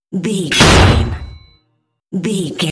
Dramatic hit metal clink
Sound Effects
Atonal
heavy
intense
dark
aggressive
hits